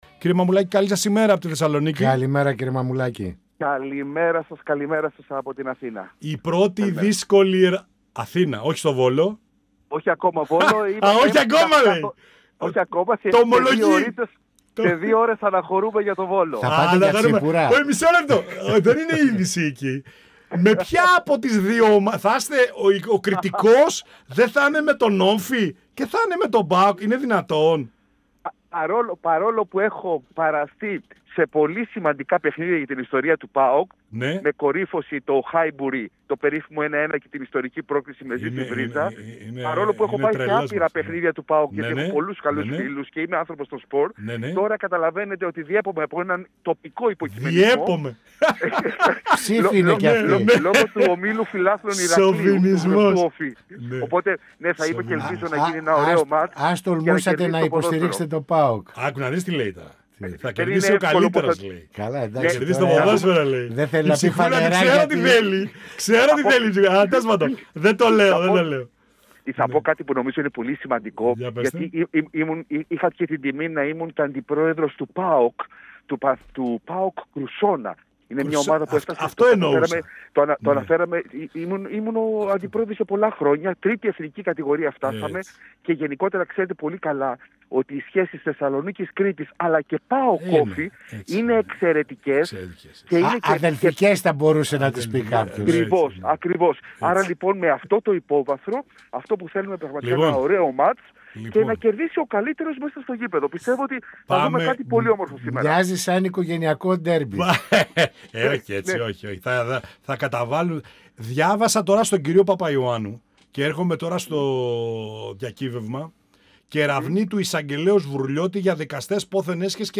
Ο Βουλευτής Ηρακλείου του ΣΥΡΙΖΑ-Π.Σ. Χάρης Μαμουλάκης, στον 102FM της ΕΡΤ3 | «Πανόραμα Επικαιρότητας» | 25.04.2026
Στις διεργασίες στο χώρο της Κεντροαριστεράς, το αναμενόμενο νέο κόμμα του Αλέξη Τσίπρα και τη στάση που θα τηρήσει ο ΣΥΡΙΖΑ-ΠΣ και οι βουλευτές του, ενόψει των επερχόμενων εκλογών, είτε γίνουν πρόωρα, είτε στην ολοκλήρωση της τετραετίας την Άνοιξη του 2027 αναφέρθηκε ο Βουλευτής Ηρακλείου του ΣΥΡΙΖΑ-Π.Σ. Χάρης Μαμουλάκης, μιλώντας στην εκπομπή «Πανόραμα Επικαιρότητας» του 102FM της ΕΡΤ3.